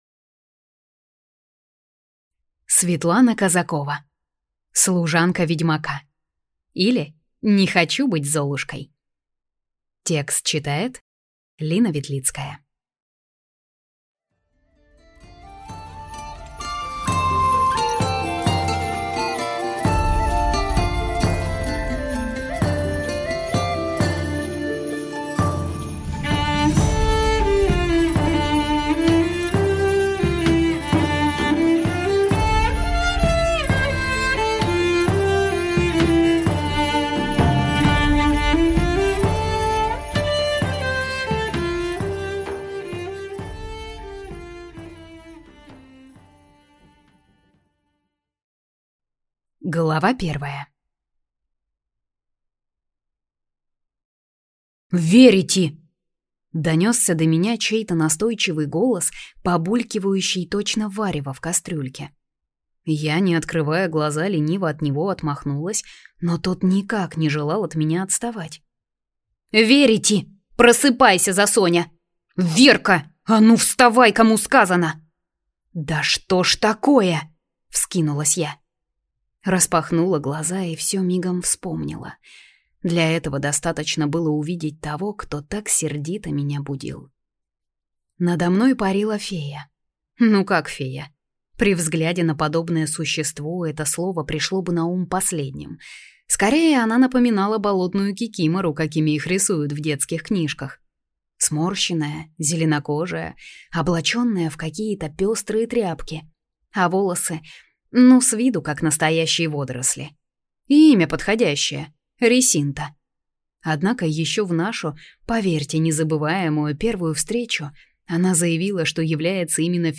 Аудиокнига Служанка ведьмака, или Не хочу быть Золушкой | Библиотека аудиокниг